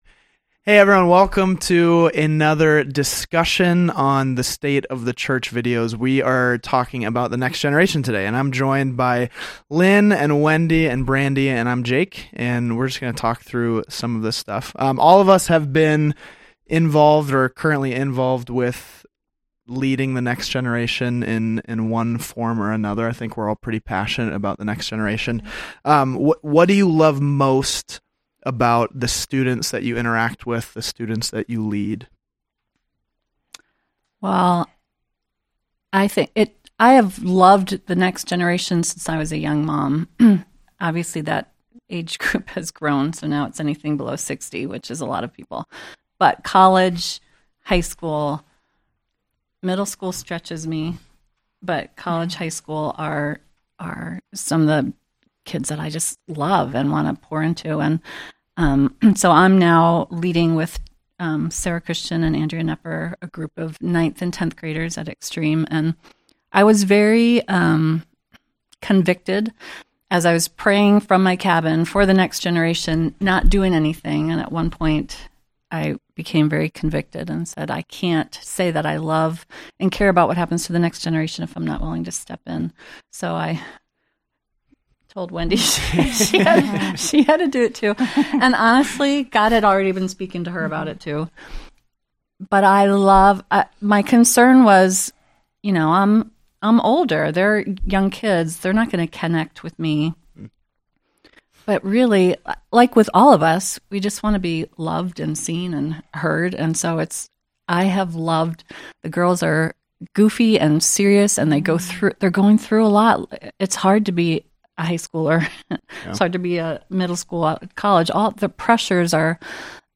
Ep 20. State of the Church | A Conversation about Reaching the Next Generation | Calvary Portal | Calvary Portal